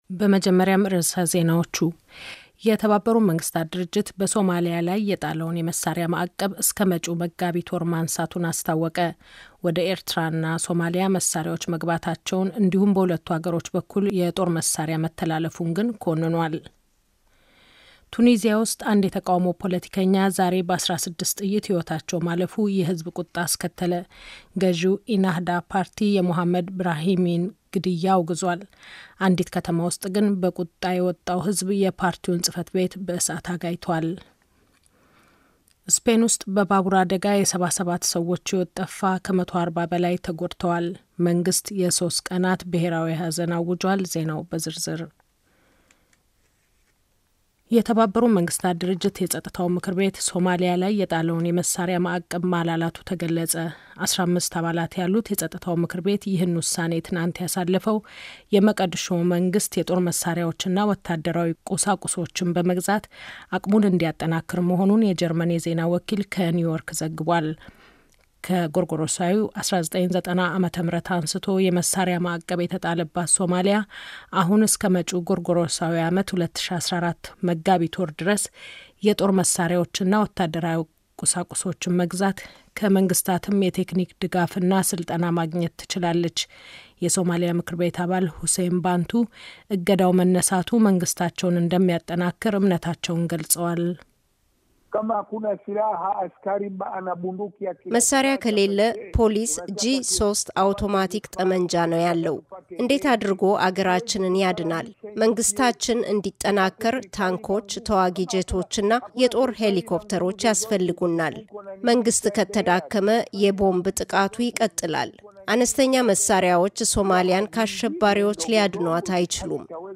የዓለም ዜና